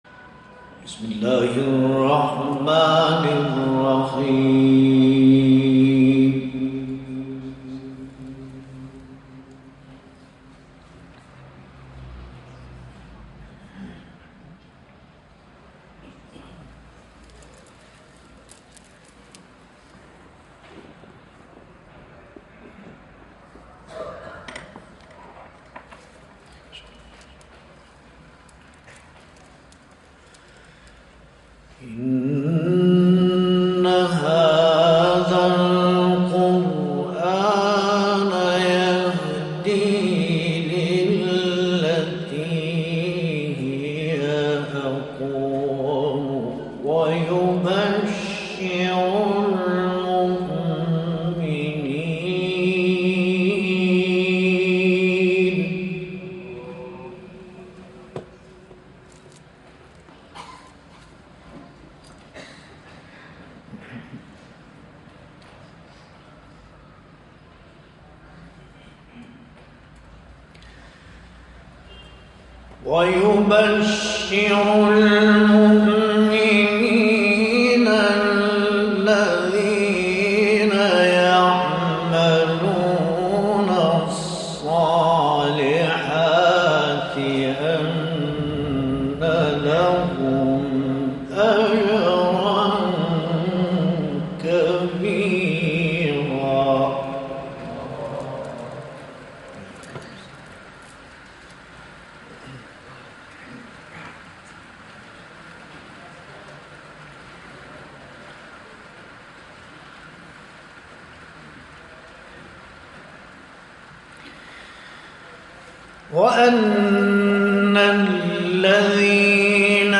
تلاوت آیاتی از سوره‌های مبارکه اسراء و شمس
در کشور ترکیه اجرا شده است